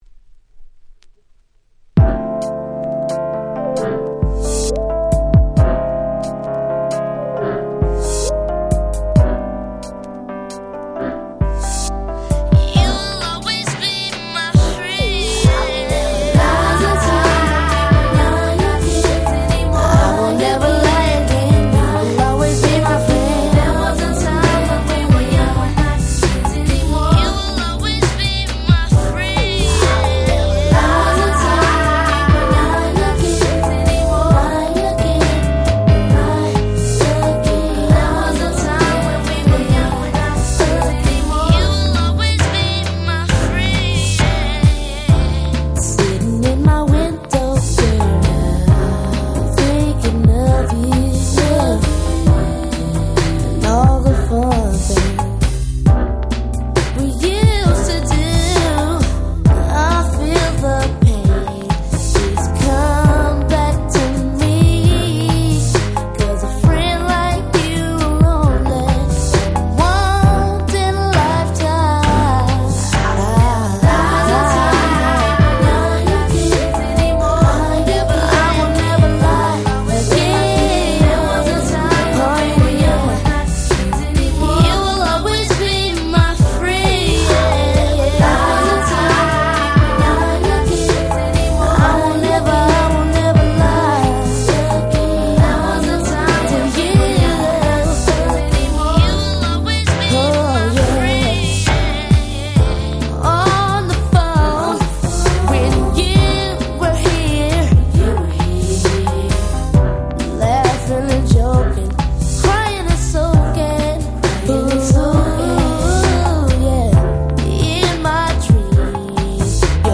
94' Super Hit Slow Jam !!